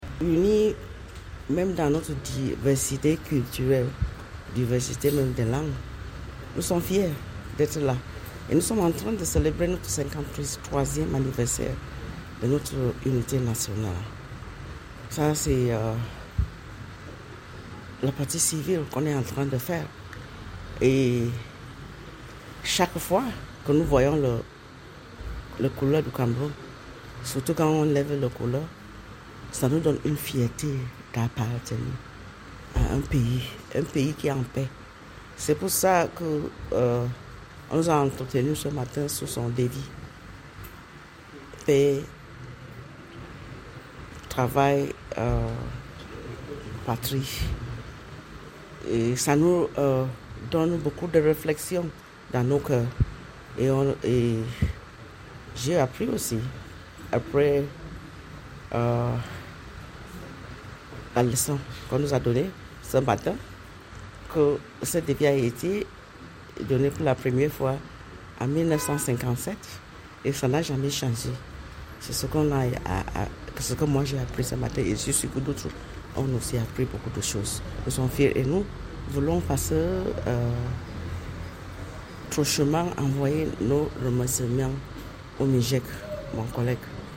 Interview Mbah Acha Rose Ministre délégué à la présidence chargé du contrôle de l’État🎤